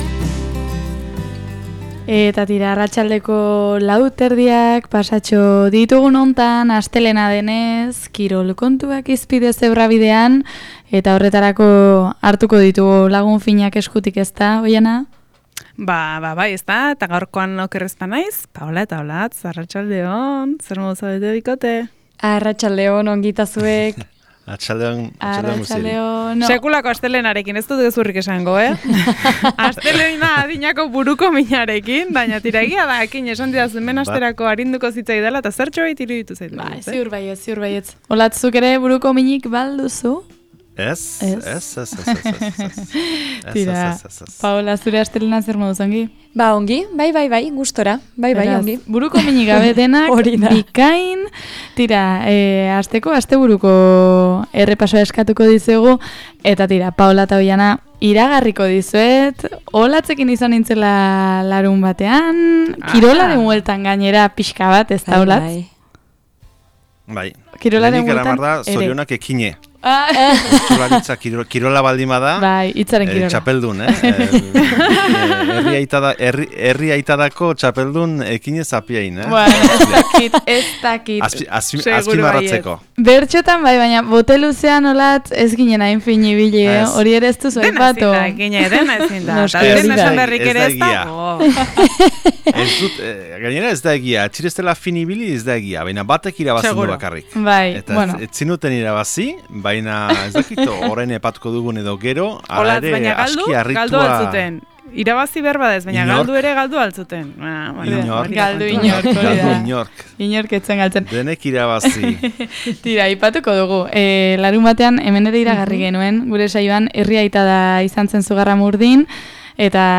Bote-luzeaz, mendi-lasterketez… · Kirol tertulia 05.26